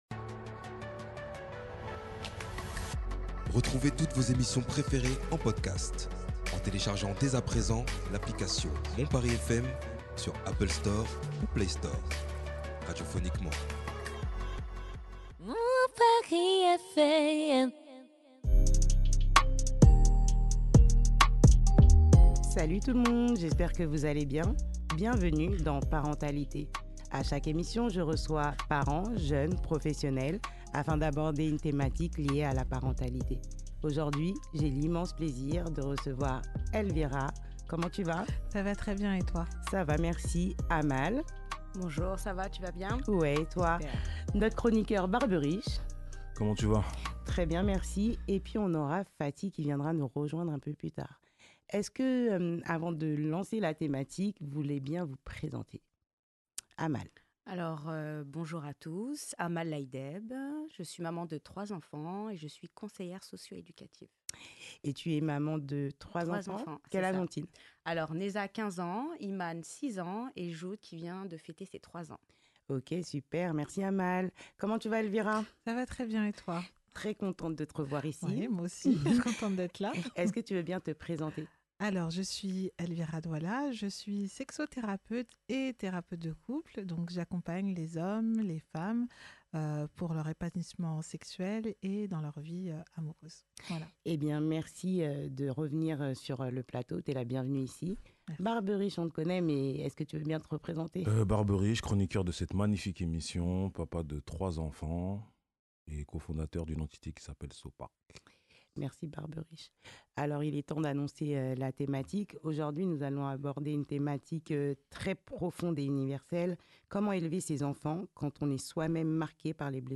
L'émission Parentali'Thé est un plateau de discussion où les sujets liés à la parentalité, l'éducation des parents et des enfants sont abordés de manière décomplexée.
Autour d'une tasse de thé, des professionnels et des familles partagent leur point de vue sur diverses thématiques.